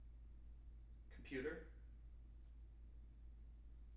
wake-word
tng-computer-362.wav